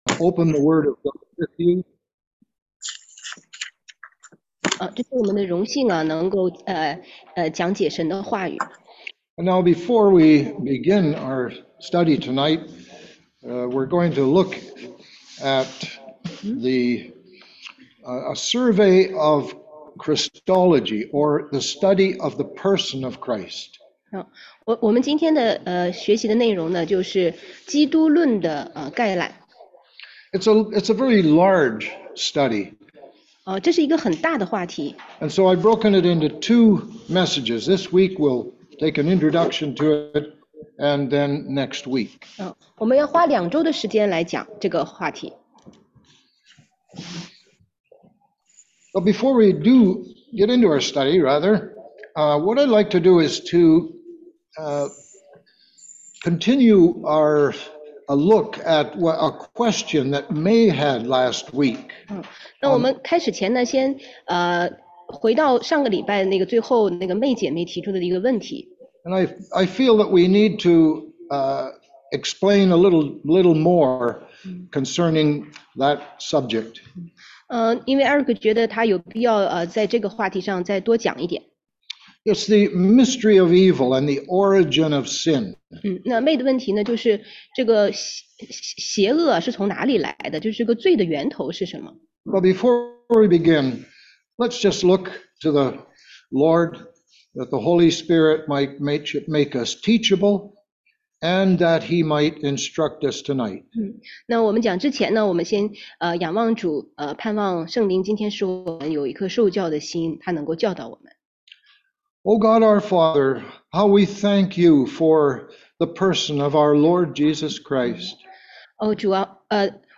16街讲道录音 - 基督论概述（1）— 基督的名字和旧约关于基督的预言
中英文查经
初级门徒培训第二课（上）.mp3